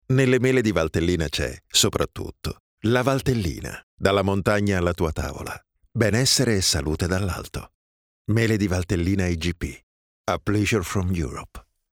Male
Versatile, Corporate, Deep, Warm
My voice has a low tone with a warm tone and is suitable for commercials institutional and other advertising agencies, telephone answering machines, documentaries, jingles, audio books, audio guide, e-learning, voice over, multimedia audio dubbing
Audio equipment: The recordings are made in my home studio equipped with soundproof booth, Neumann tlm 103 microphone, Apollo MKII SOUND CARD which guarantees white quality